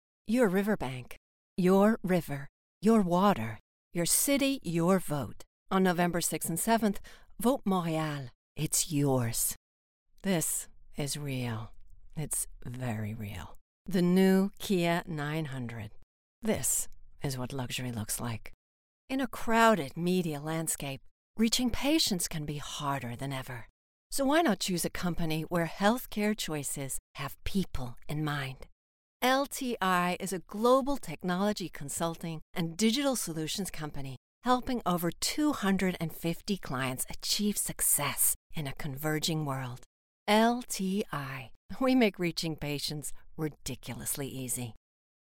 Commercial (American accent) - EN